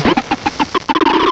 sound / direct_sound_samples / cries / hippopotas.aif
hippopotas.aif